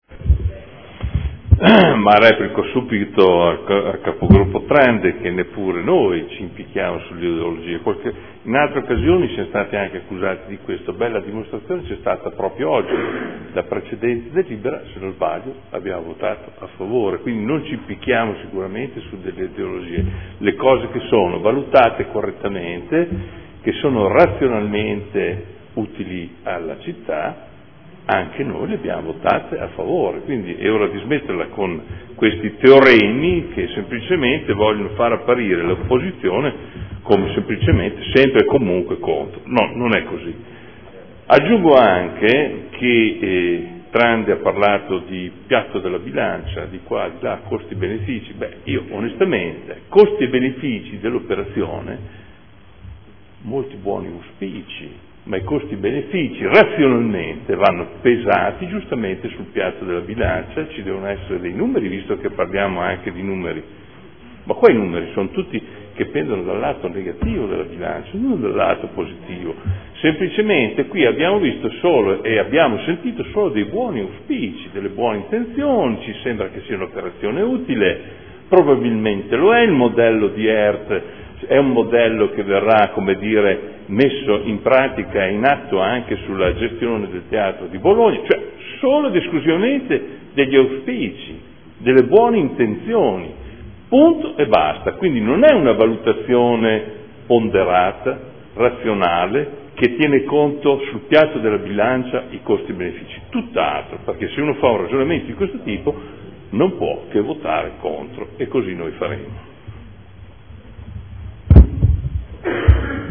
Adolfo Morandi — Sito Audio Consiglio Comunale
Seduta del 23/01/2014 Dichiarazione di Voto. Adesione del Comune di Bologna a Emilia Romagna Teatro Fondazione in qualità di socio fondatore necessario.